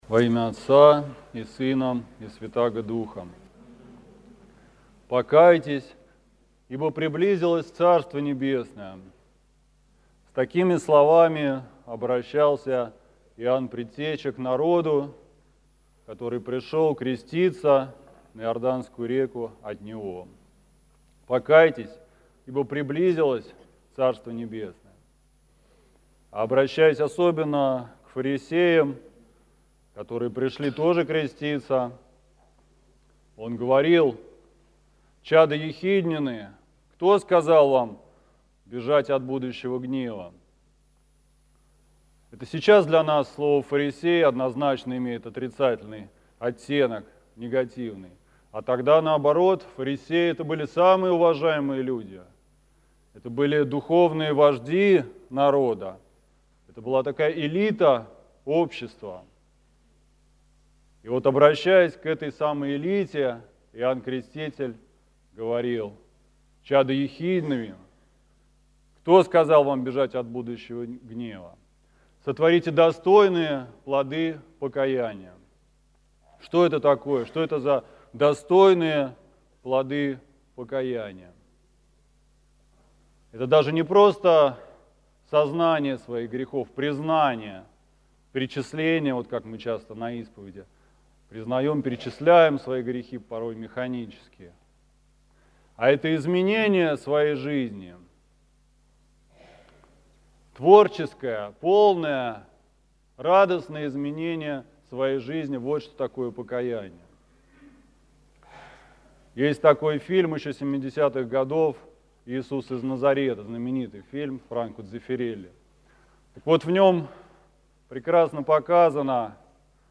Слово на Крещение Господне